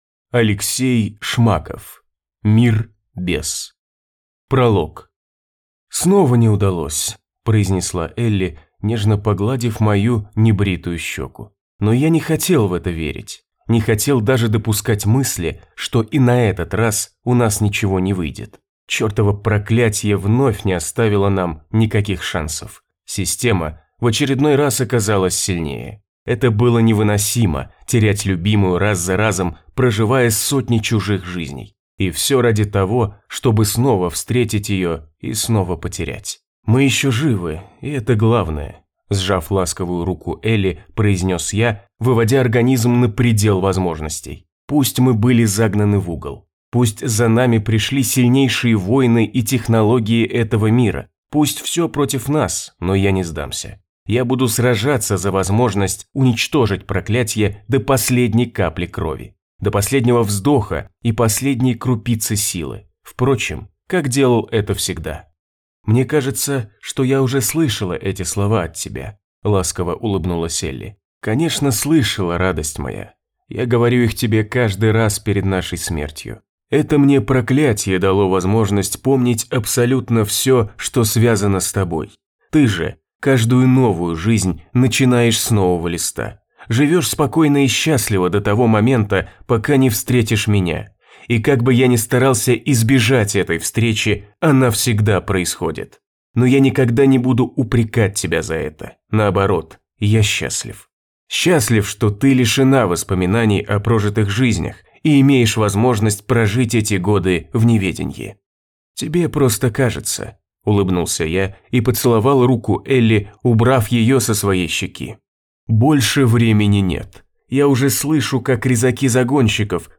Аудиокнига Мир без… | Библиотека аудиокниг
Прослушать и бесплатно скачать фрагмент аудиокниги